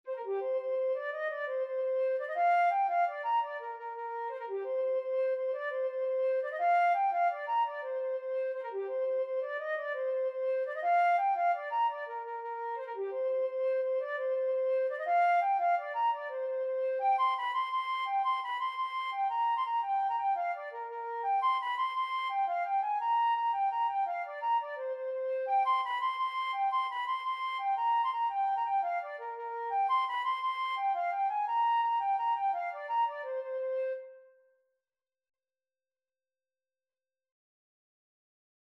Flute version
6/8 (View more 6/8 Music)
G5-C7
Flute  (View more Intermediate Flute Music)
Traditional (View more Traditional Flute Music)